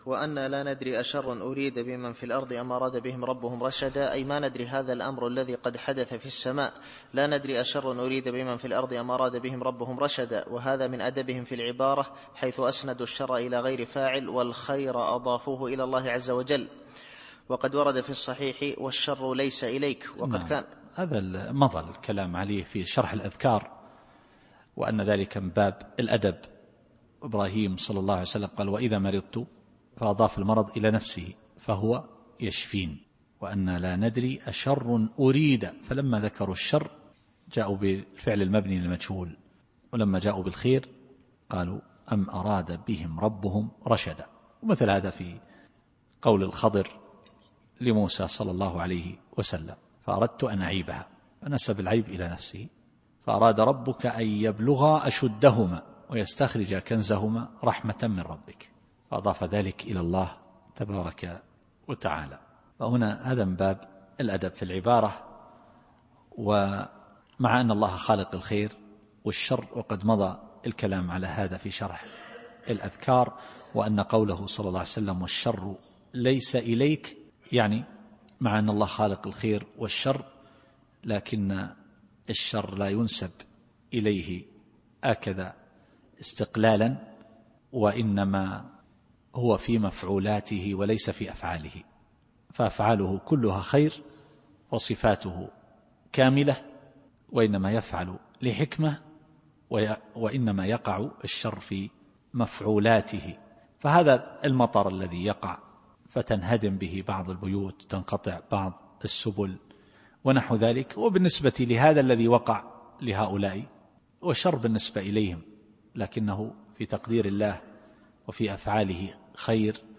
التفسير الصوتي [الجن / 10]